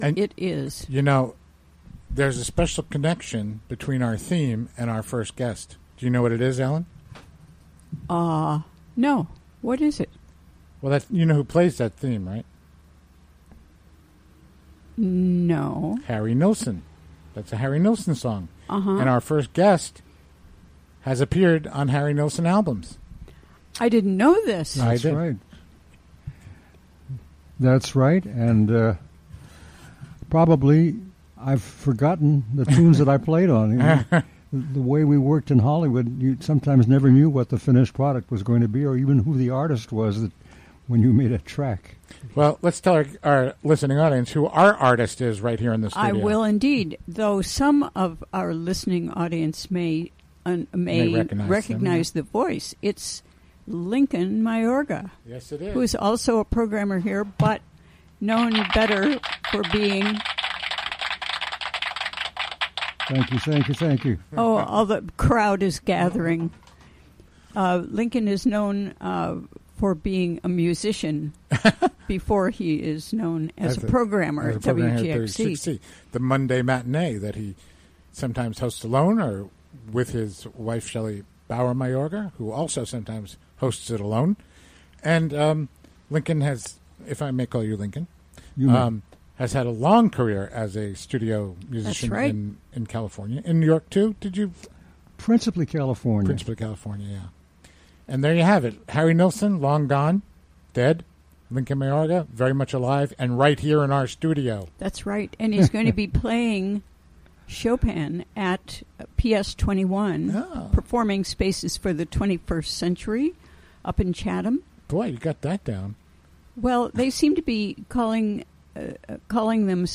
Recorded during the WGXC Afternoon Show Thursday, July 13, 2017.